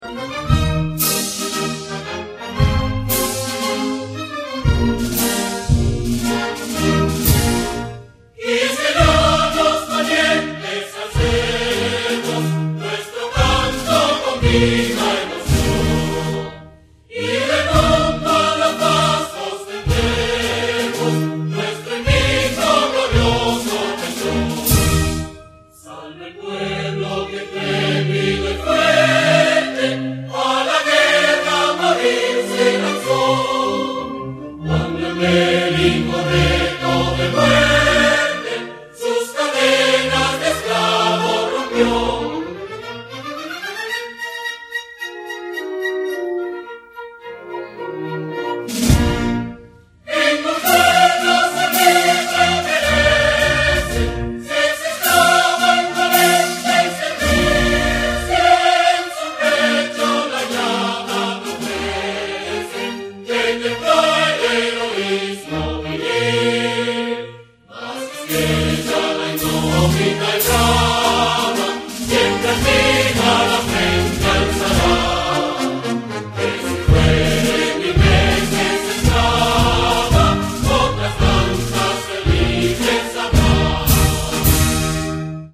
orkest met koor mp3 (1,3 mb!)